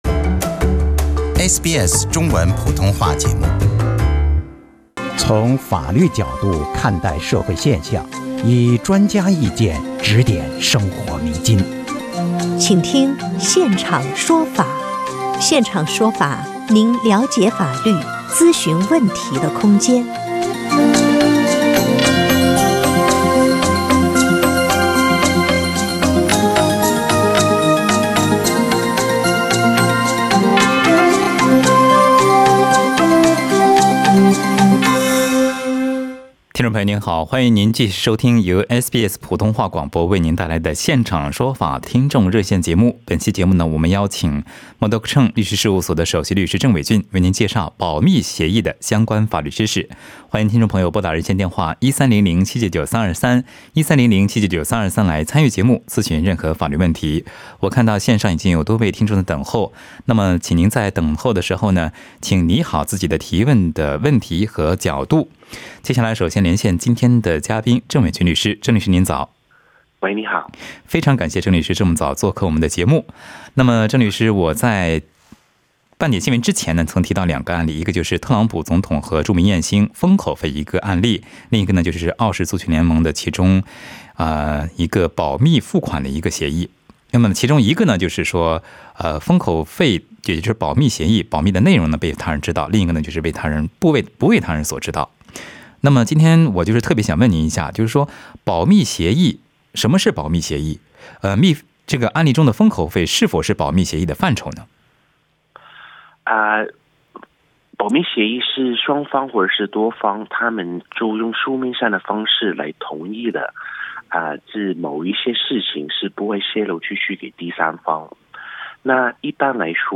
现场说法节目嘉宾律师作答 保密协议具有什么样的法律效力？
另外，有听众在热线节目中咨询了法律问题。